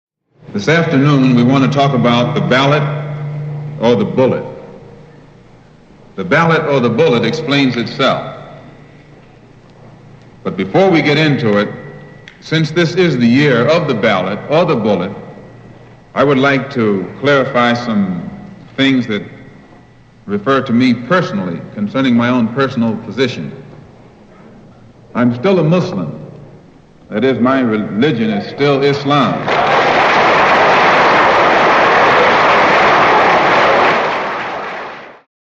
Malcolm X fera de nombreux discours et notamment sur le vote des Noirs dont celui-ci :